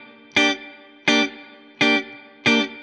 DD_StratChop_85-Gmin.wav